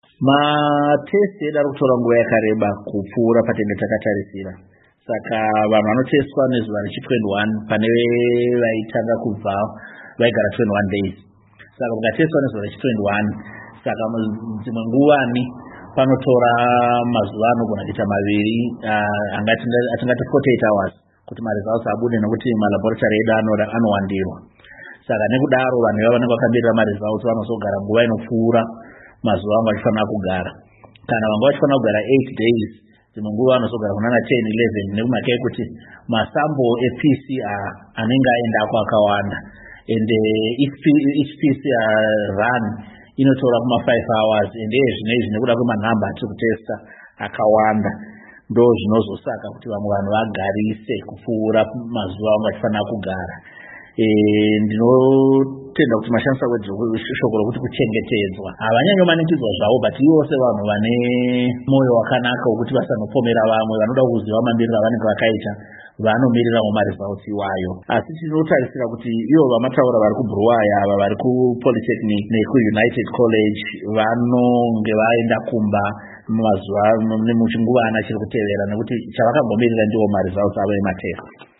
VaNick Mangwana Vachitsanangudza Pamire Hurumende